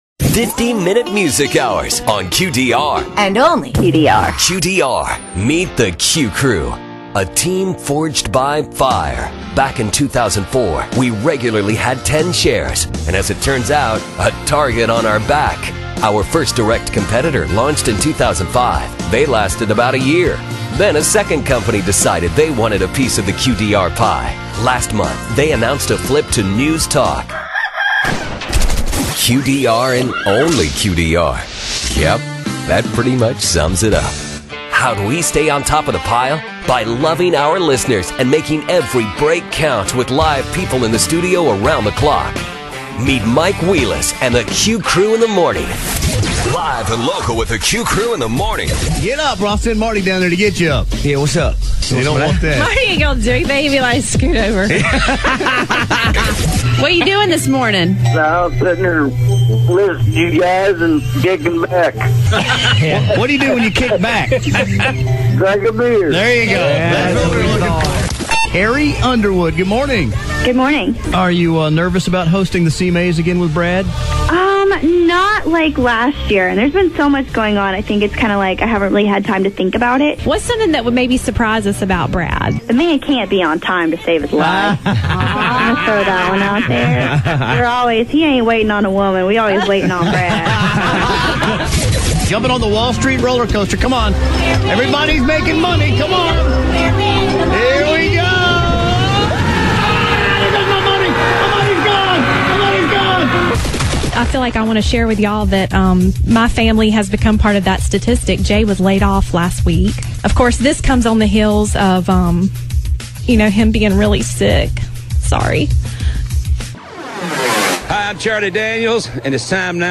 2010 6:50 pm A country radio- but no news so far. http
qdr_aircheck.wma